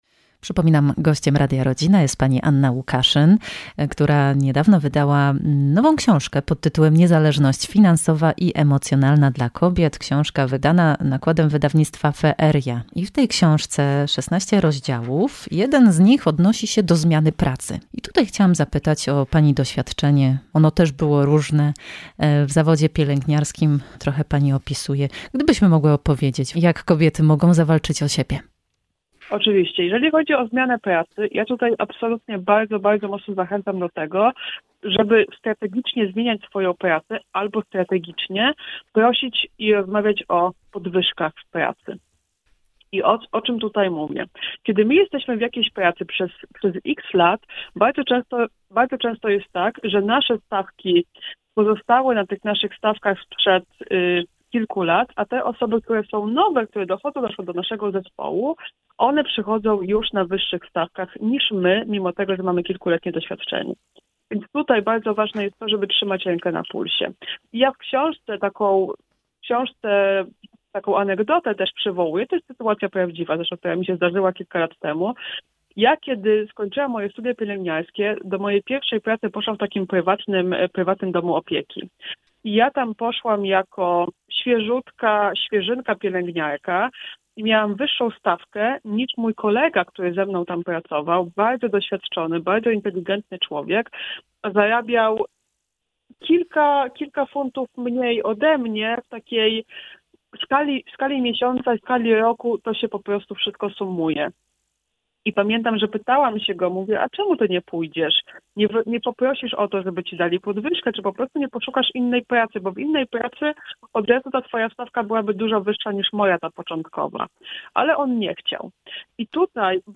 Emisja wywiadu we wtorek 30 stycznia po godz. 16:10.